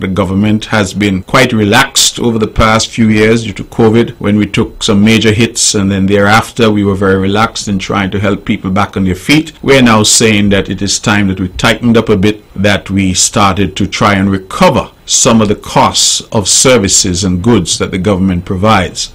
That was the voice of Premier of Nevis, Hon. Mark Brantley.